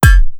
KillingShot_Sound.wav